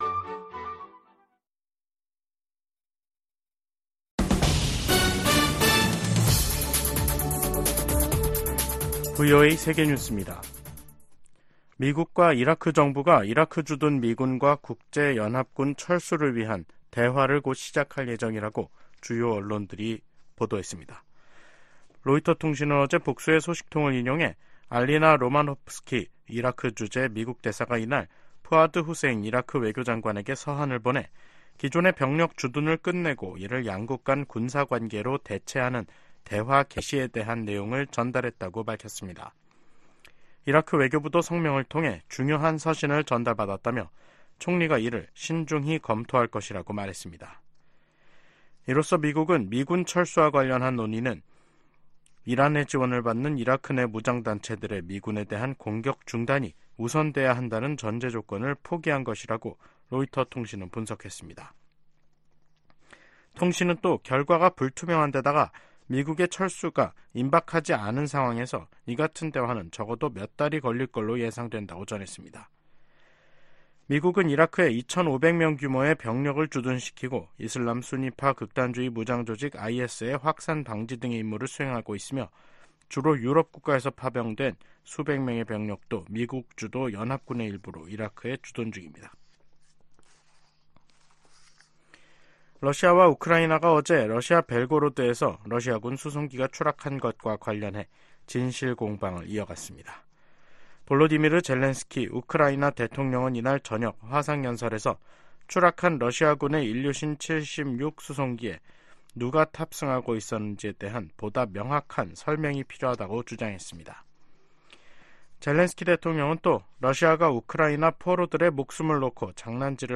VOA 한국어 간판 뉴스 프로그램 '뉴스 투데이', 2024년 1월 25일 2부 방송입니다. 북한이 신형 전략순항미사일을 첫 시험발사했다고 밝혔습니다.